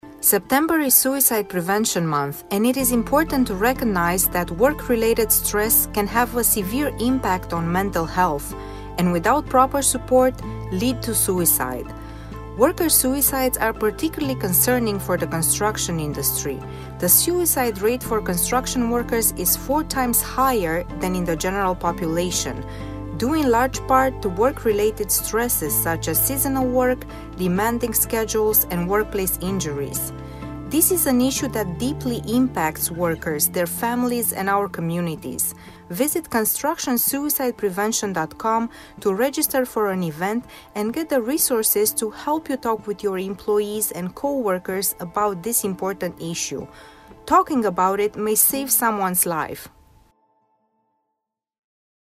PSA_suicide_prevention_english_2022.mp3